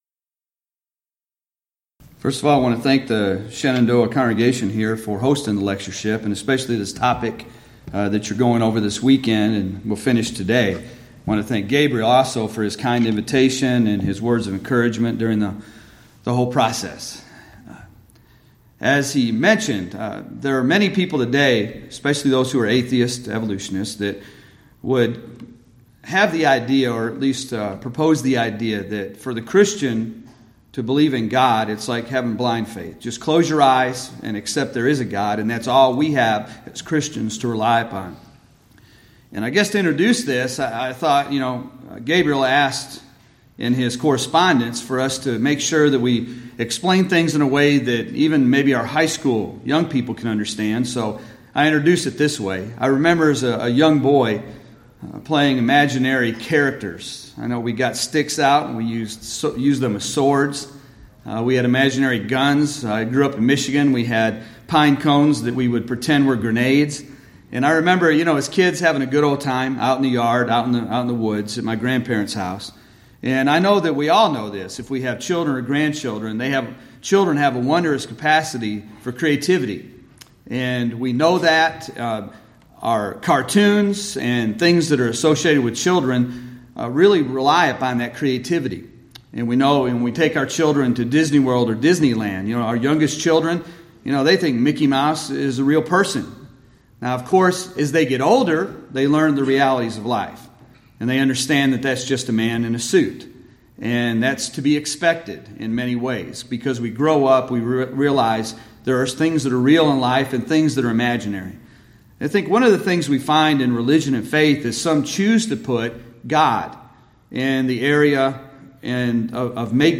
Alternate File Link File Details: Series: Shenandoah Lectures Event: 27th Annual Shenandoah Lectures Theme/Title: The Evolution of Enlightenment: Can Science and Religion Co-Exist?
lecture